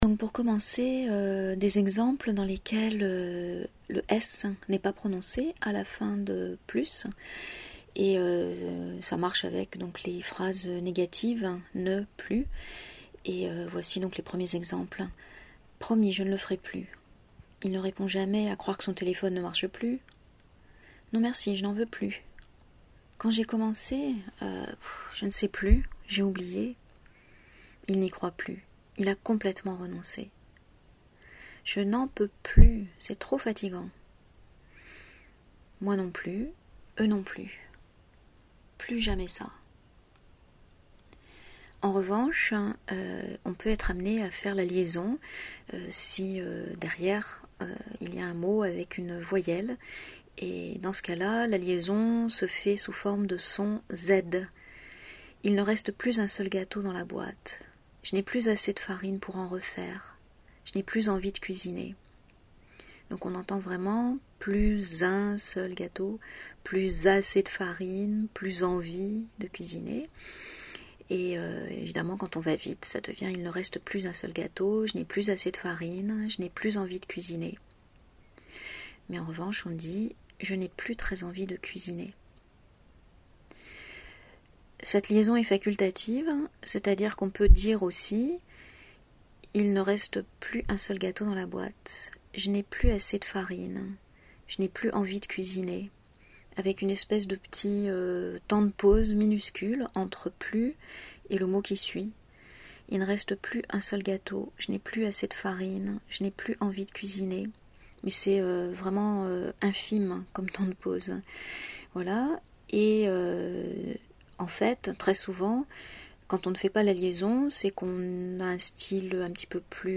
Et bien sûr, comme c’est un problème de prononciation, je vous ai enregistré tous ces exemples ici :
plus-la-prononciation.mp3